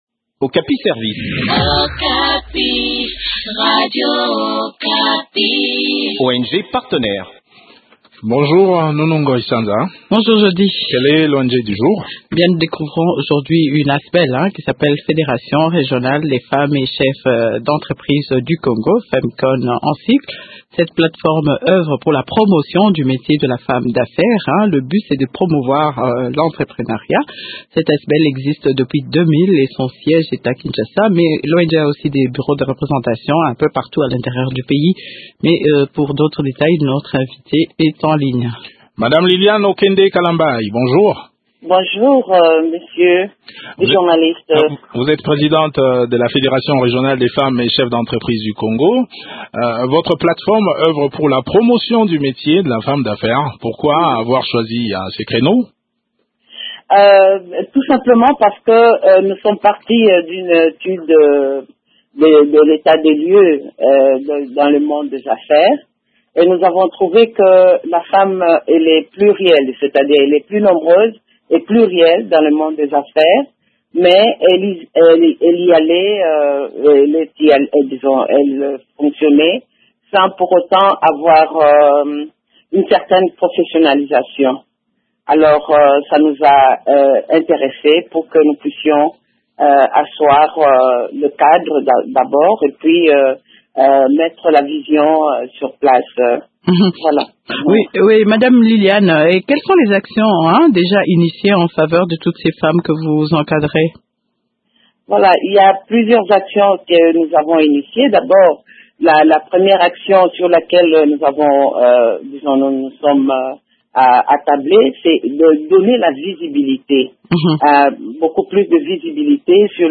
Le point sur les activités de cette structure dans cet entretien